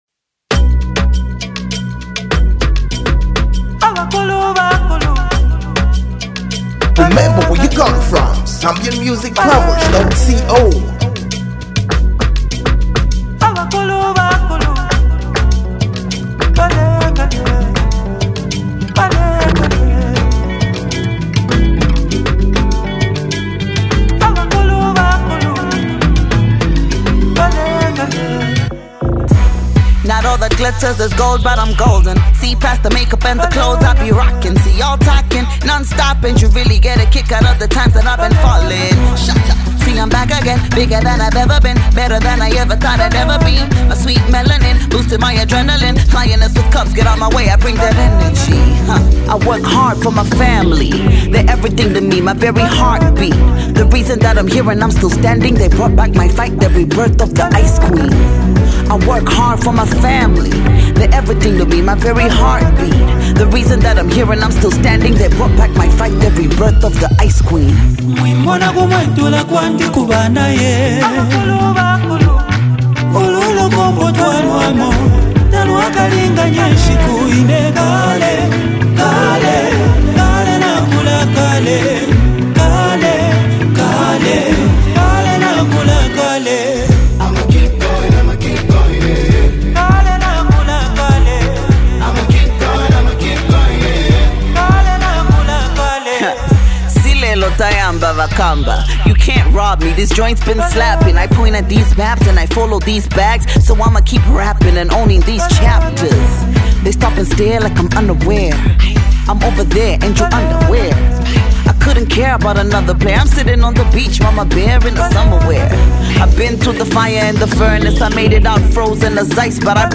one of Zambia’s most celebrated female rappers
energetic rap verses